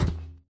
mob / irongolem / walk4.ogg
walk4.ogg